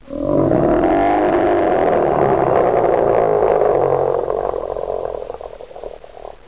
Bear Groan
bear-groan-3.ogg